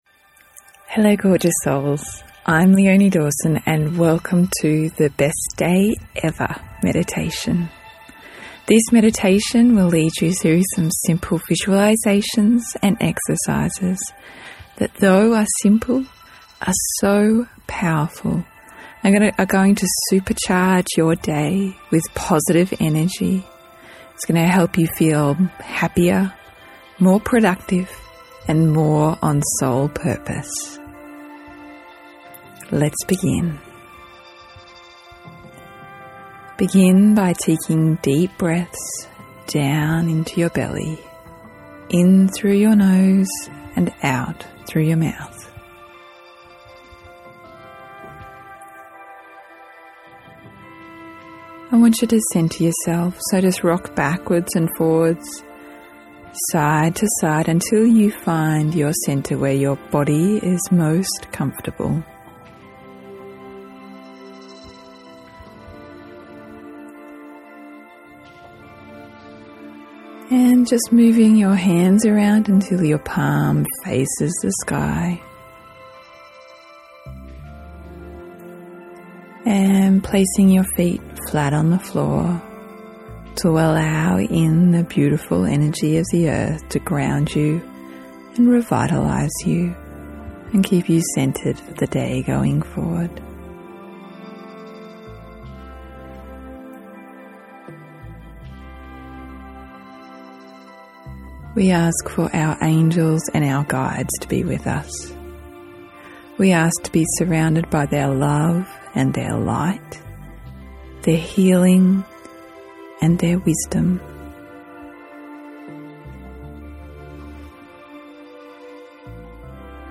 Listen to this short (under 10 minute) affirmation meditation now to feel a noticeable shift in your day!
Best+Day+Ever+Affirmation+Meditation.mp3